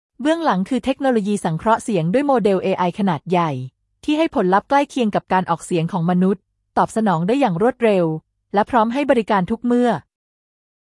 TTS语音合成
这一代 TTS 不再是“机器发声”，而是可以真正传递情感和温度的 AI 声音，在自然度、韵律、口气、情绪、语气词表达等方面全面突破，让听者几乎无法分辨“人声”与“机器声”。